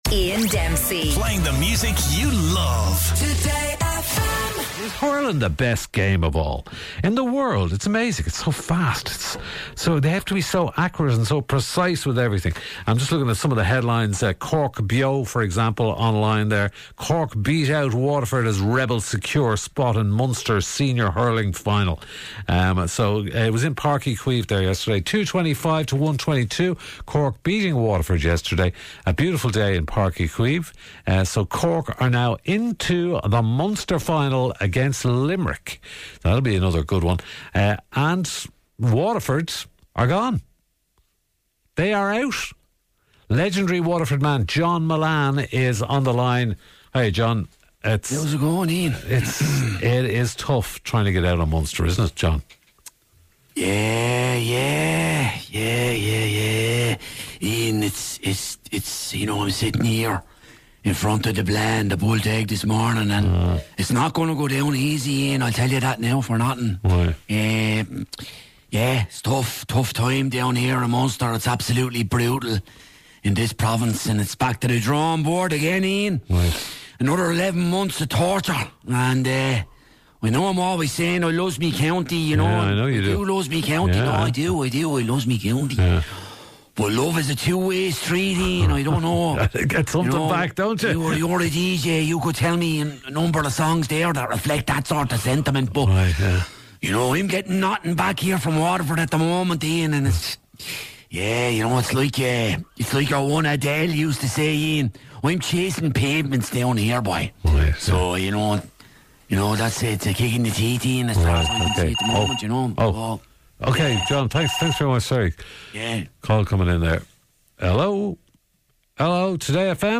With word of a government 'housing czar' being appointed to sort out the Irish housing crisis, this morning's Gift Grub saw the government burst into song.